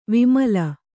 ♪ vimala